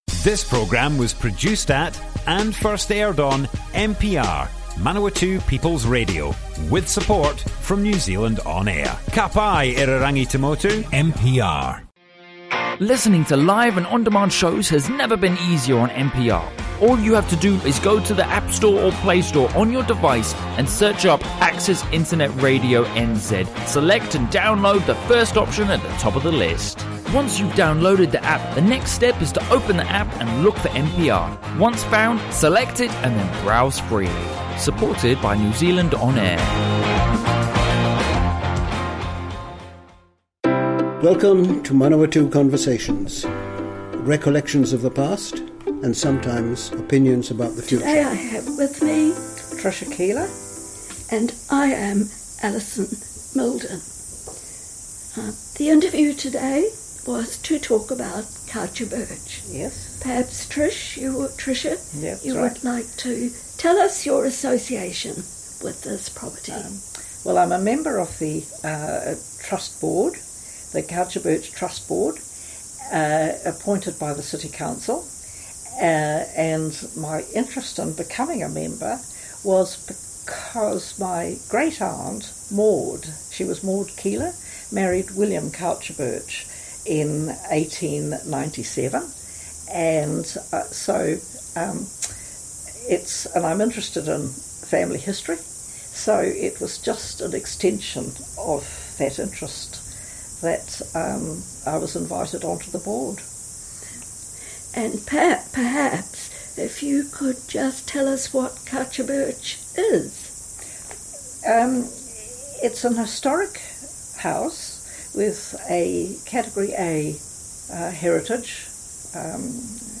Manawatū Conversations More Info → Description Broadcast on Manawatū People's Radio, 7 May, 2019.
Sound of cicadas in the background.